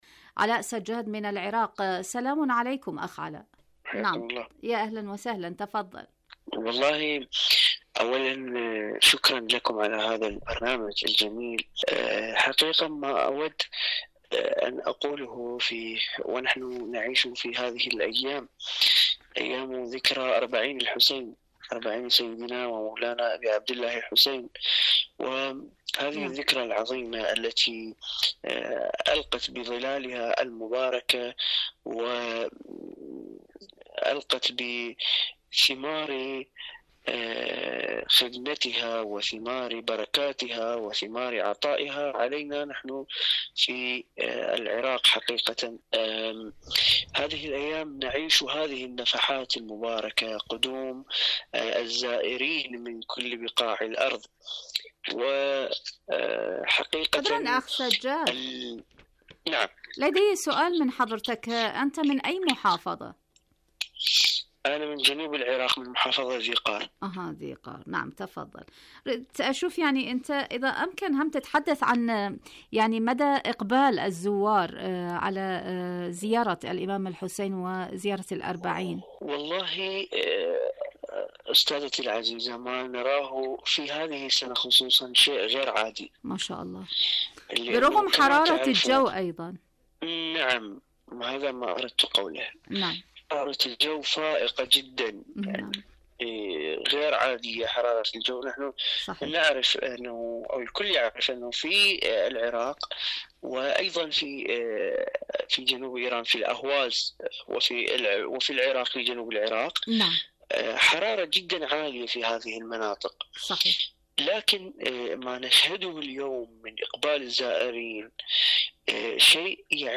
مشاركة صوتية